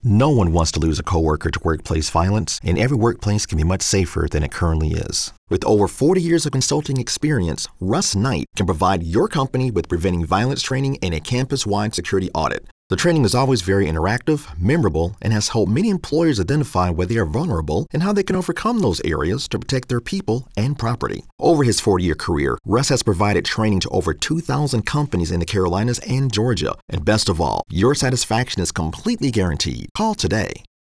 Radio Advertisement